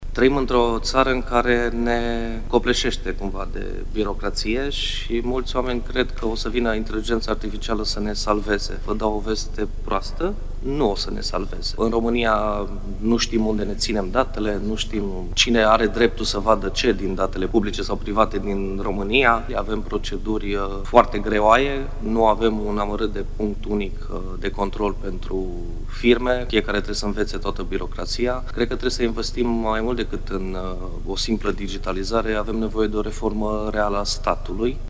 Digitalizarea statului român este greoaie și copleșită: cu greu se poate discuta despre implementarea inteligenței artificiale în procese, dacă nu rezolvăm primele probleme, afirmă președintele Comisiei pentru Comunicații și Tehnologie din Senat, la o conferință organizată de publicația CursDeGuvernare.